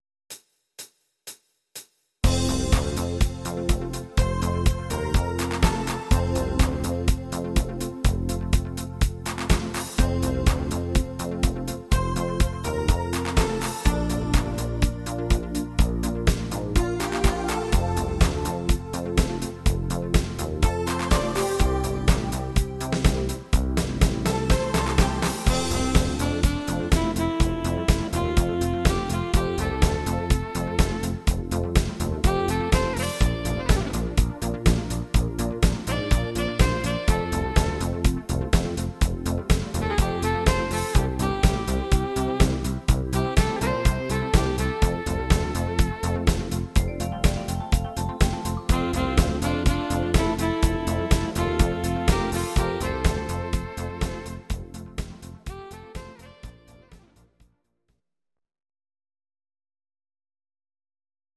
Instrumental Sax